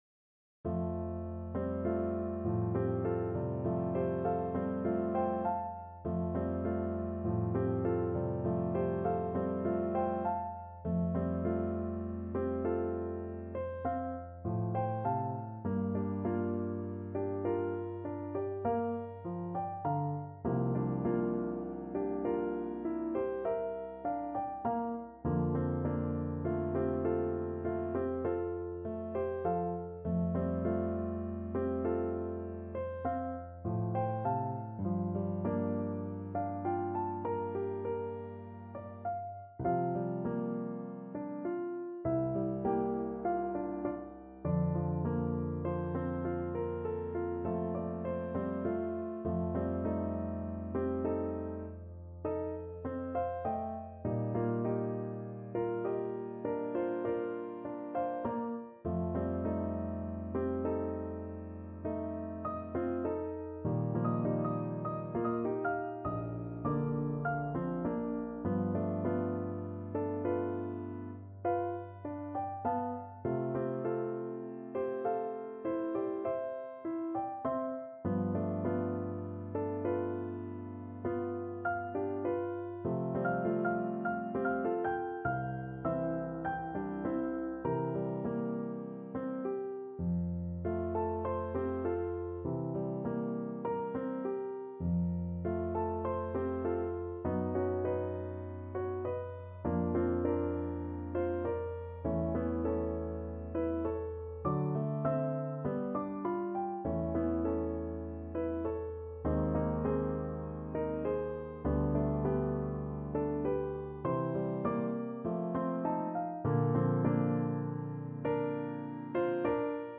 Lento =50
Classical (View more Classical Saxophone Music)